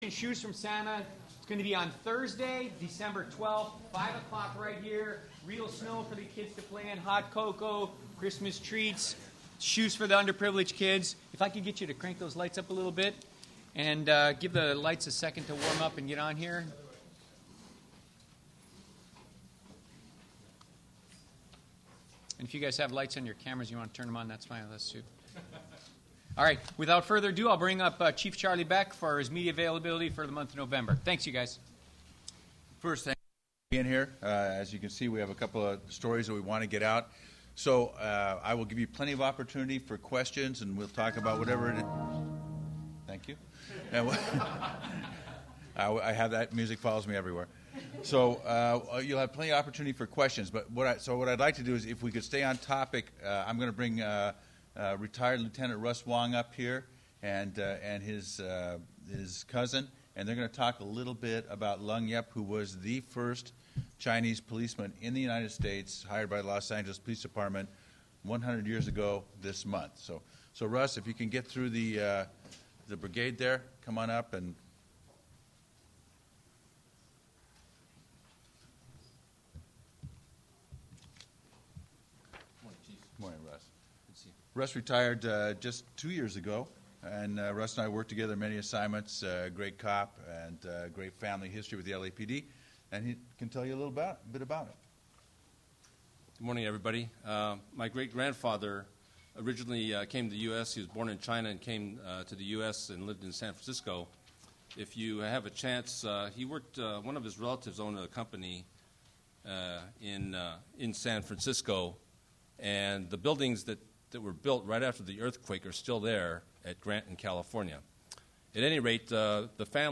On Wednesday November 7, 2024 Chief of Police Charlie Beck held his monthly media availability to discuss several issues with members of the press. Chief Beck provided crime statistics, introduced a new public service announcement (PSA) created by the LAPD to educate citizens on appropriate use of the city’s 9-1-1 emergency call system, and provided updated information of the tragic incident at LAX which occurred on November 1, 2013.